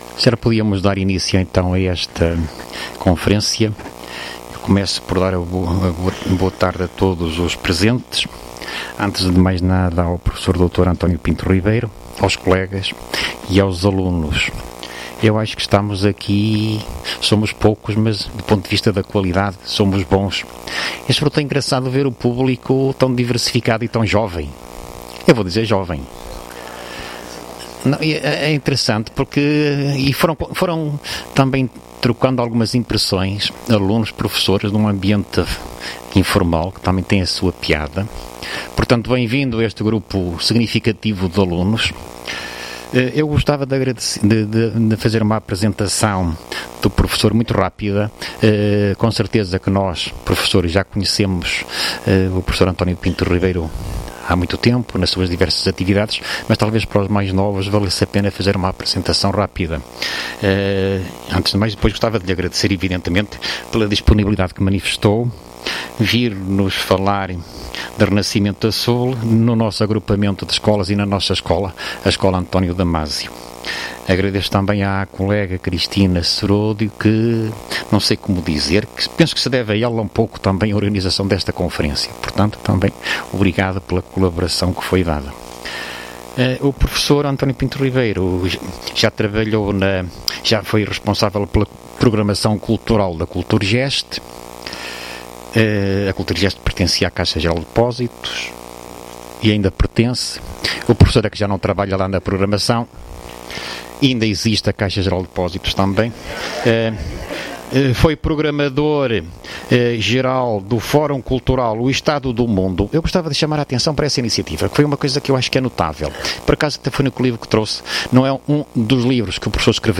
Conferência